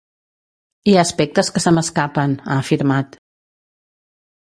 /sə/